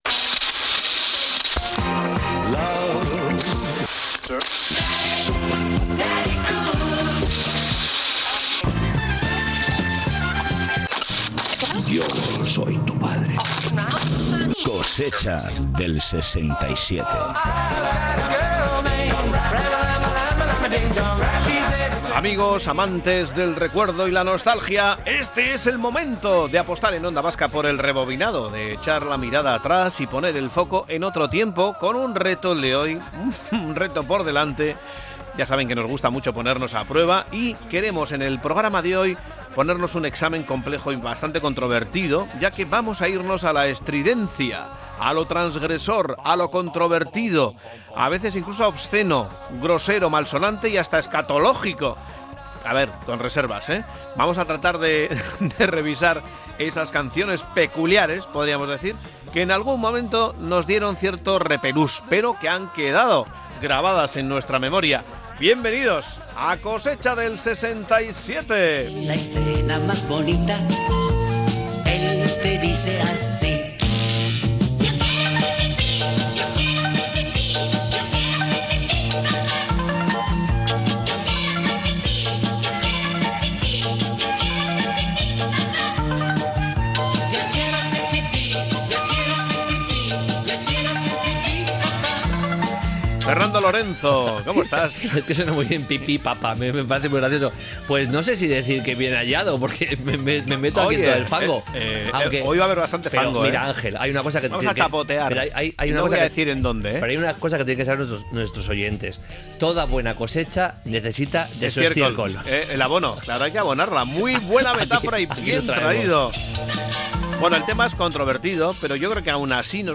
Espacio conectado a la nostalgia a través del humor y la música.